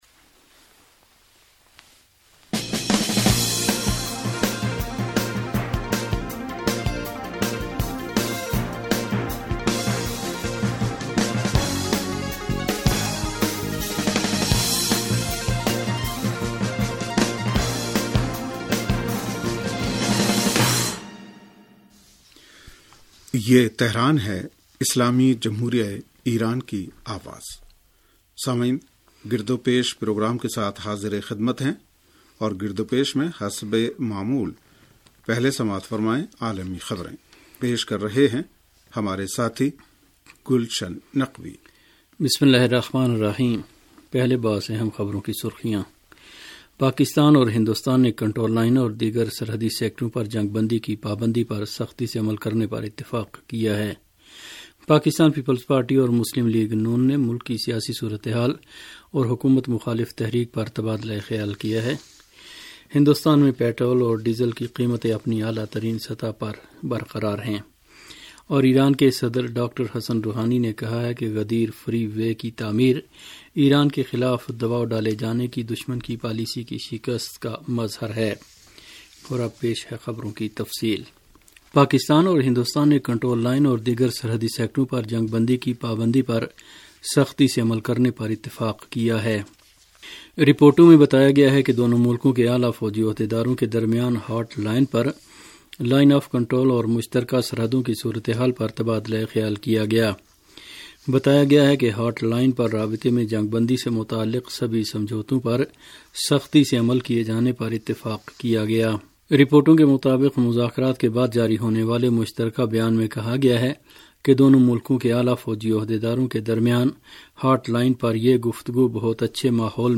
ریڈیو تہران کا سیاسی پروگرام گرد و پیش